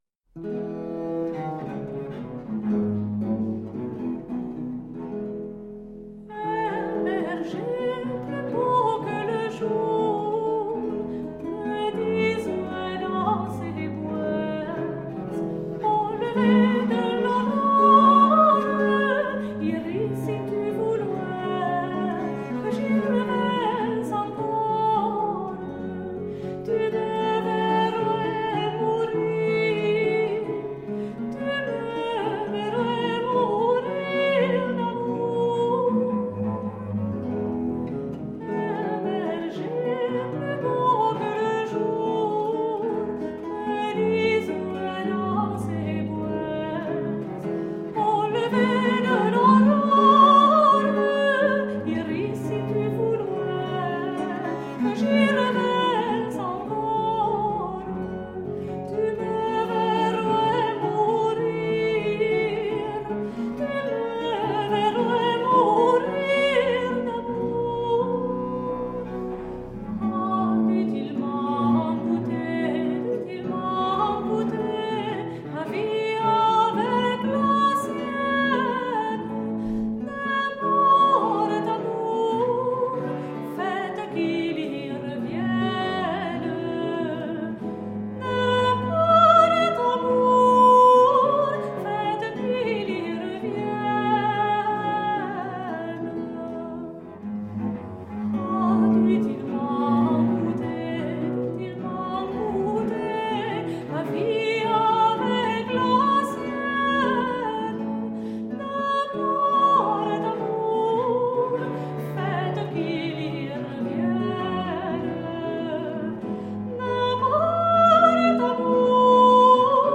Delicate 17th century chansons.
the three women
with all-original instrumentation
with the intimate feeling of a parlor room recital.